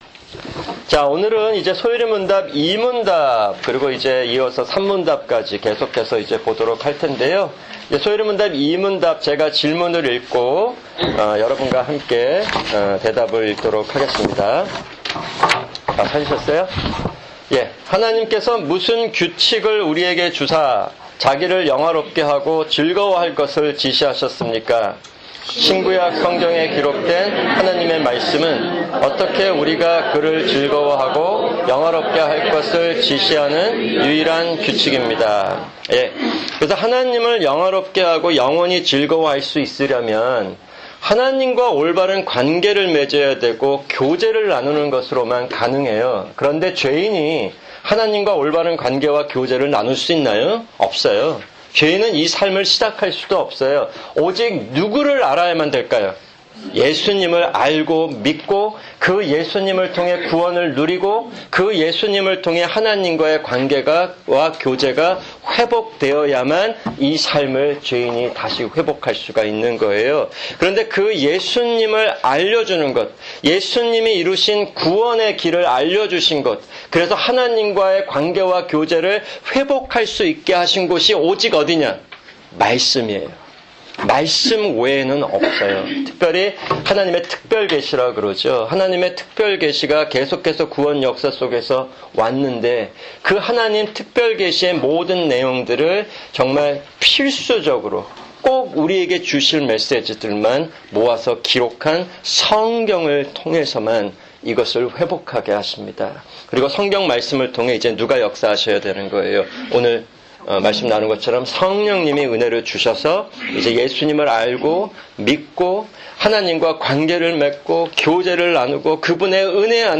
[주일 성경공부] 소요리문답-2문답(1)